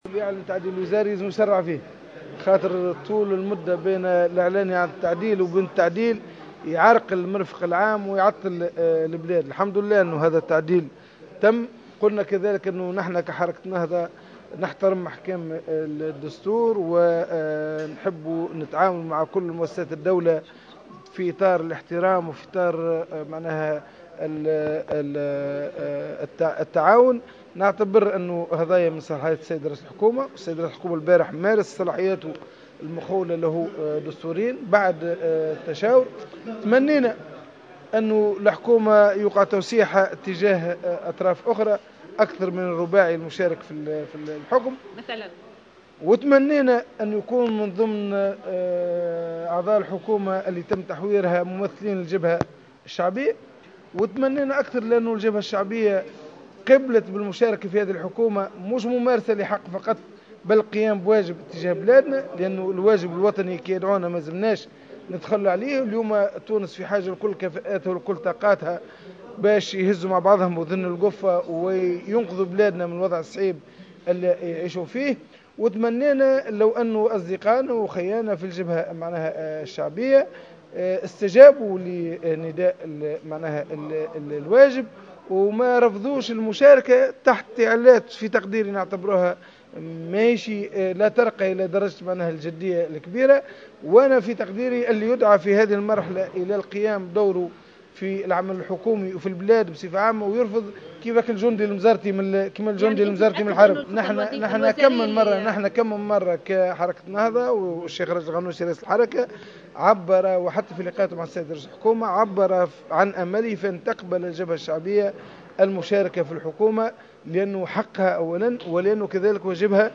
واعتبر البحيري في تصريح لمراسلة الجوهرة أف أم اليوم الخميس على هامش اجتماع لجان مجلس النواب، أن الصيد مارس صلاحياته الدستورية بعد التشاور مع مختلف الأطراف، مضيفا " تمنينا لو تم توسيع الحكومة لتشتمل على مكونات أخرى أكثر من الرباعي الحاكم، وتمنينا لو كان هنالك ممثلين للجبهة الشعبية في الحكومة الجديدة، وتونس في حاجة لكل طاقاتها لإنقاذها من وضعها الصعب".